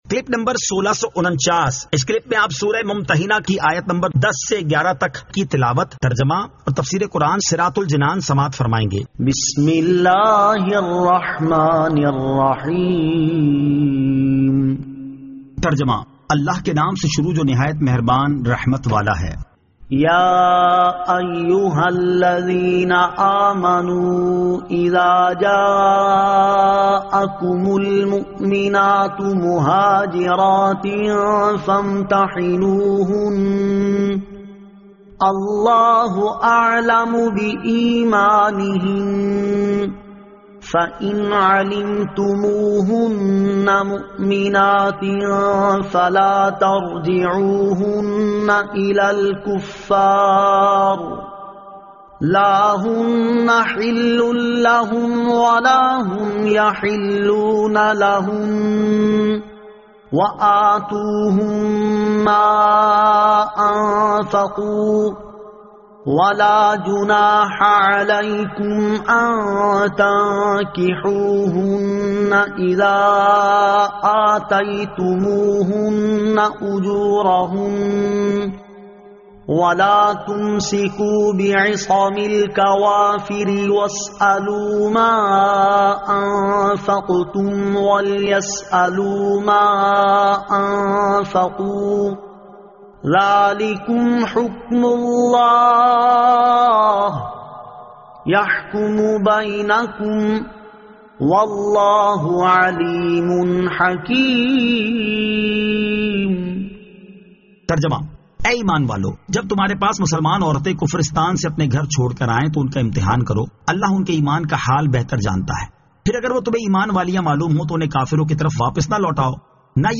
Surah Al-Mumtahinan 10 To 11 Tilawat , Tarjama , Tafseer
2024 MP3 MP4 MP4 Share سُوَّرۃُ المُمٗتَحَنَۃ آیت 10 تا 11 تلاوت ، ترجمہ ، تفسیر ۔